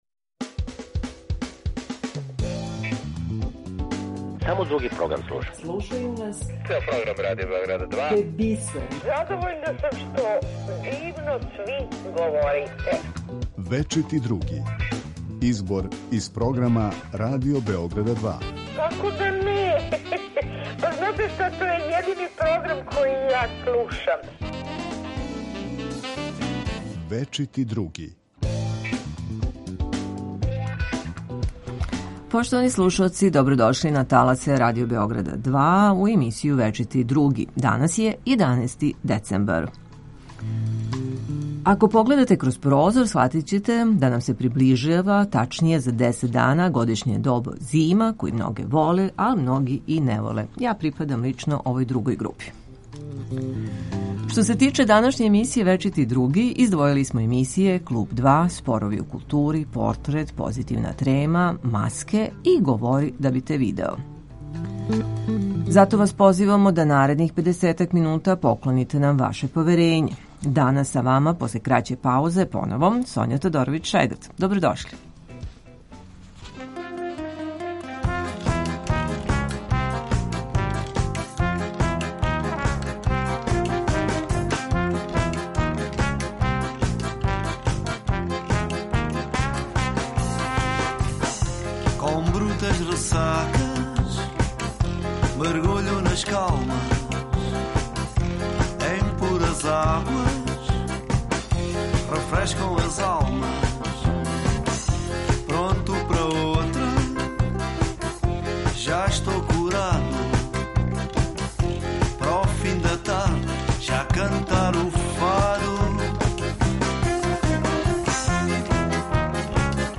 У емисији Вечити Други чућете избор из програма Радио Београда 2.